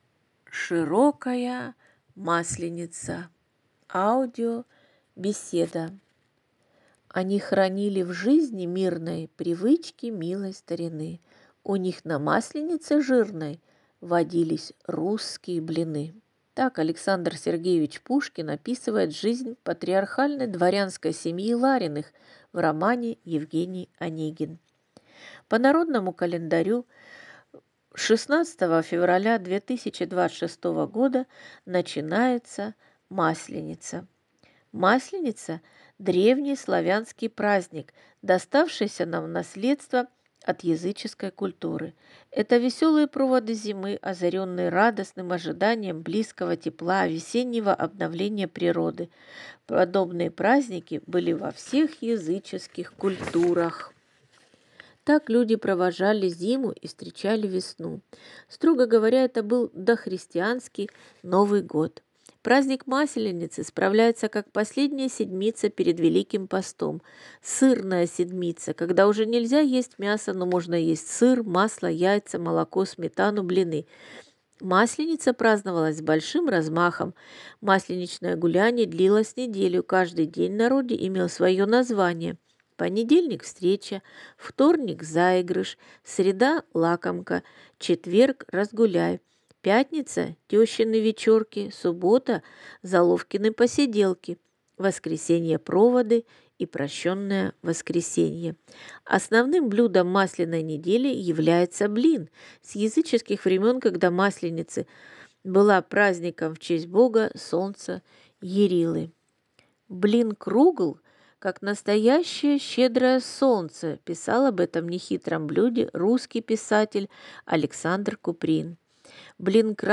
Аудиобеседа «